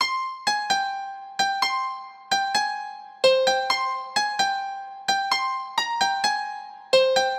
130 Bpms Trap Producers PIANO 130C
Tag: 130 bpm Trap Loops Piano Loops 2.03 MB wav Key : C